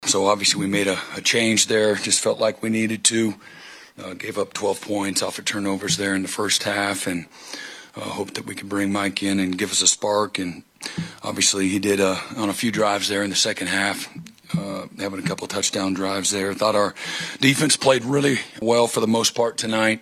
After the game head coach Brent Venables talked about the Sooner effort.